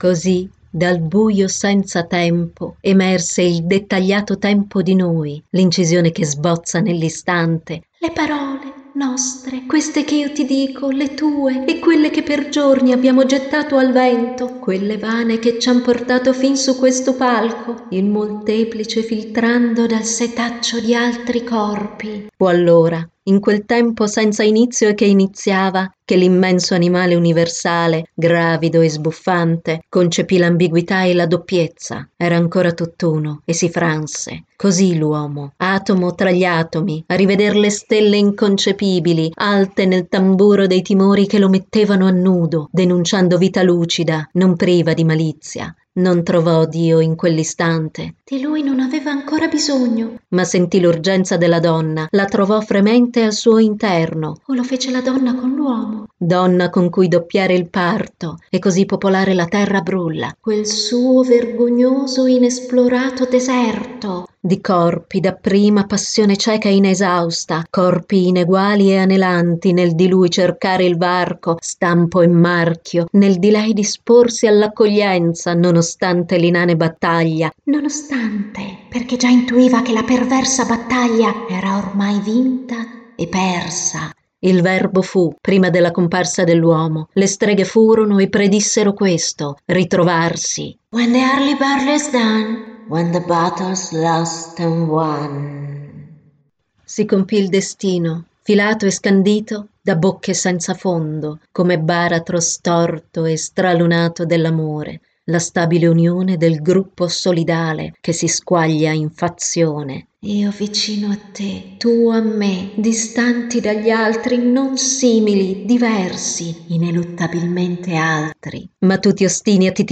letture